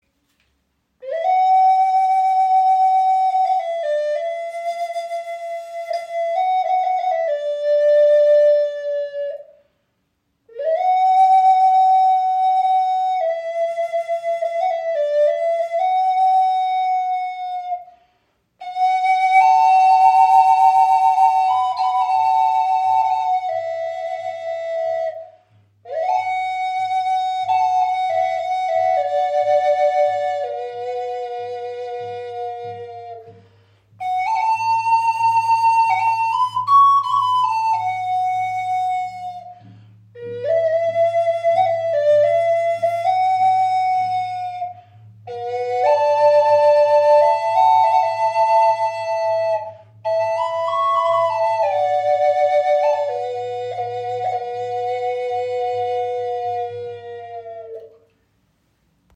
• Icon Einzigartige 6-Loch Harmonie Doppel-Okarina in Maya Stimmung
Harmonie Doppel Okarina aus Aststück | B / & F# in 432 Hz | Maya Stimmung
Jeder Ton wirkt wie ein Atemzug der Erde selbst – sanft, tief und doch klar wie der Ruf eines Vogels in der Morgendämmerung.
Trotz ihrer handlichen Grösse erzeugt sie einen angenehm tiefen und warmen Klang – fast ebenbürtig zur nordamerikanischen Gebetsflöte.